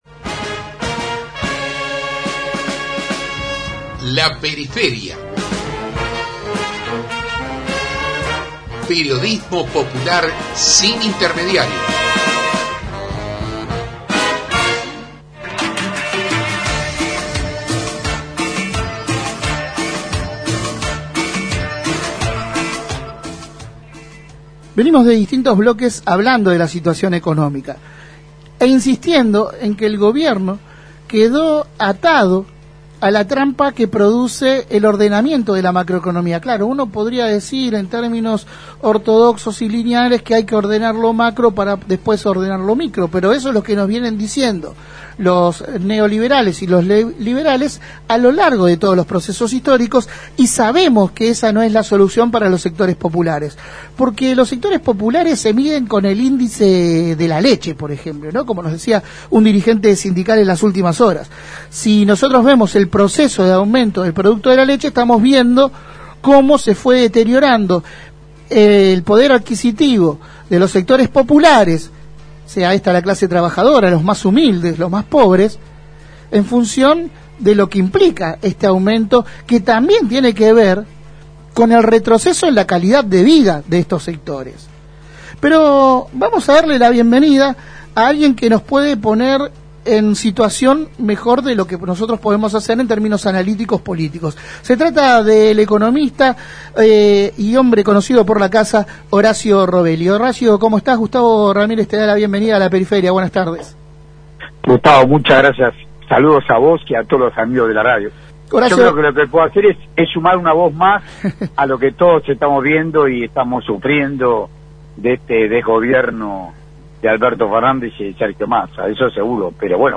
Compartimos la entrevista completa: 06/10/2022